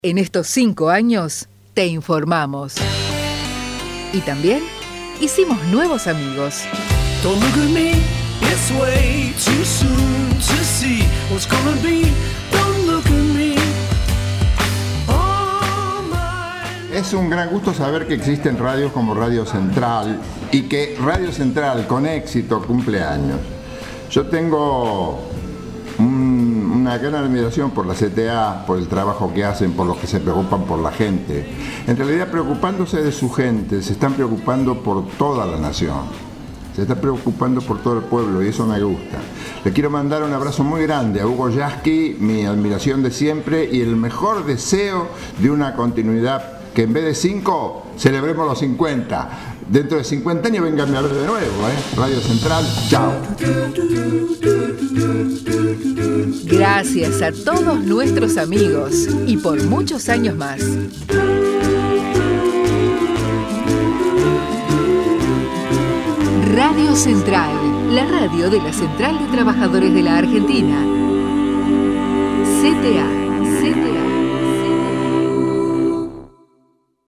HÉCTOR LARREA saluda a RADIO CENTRAL en su 5º ANIVERSARIO